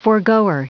Prononciation du mot foregoer en anglais (fichier audio)
Prononciation du mot : foregoer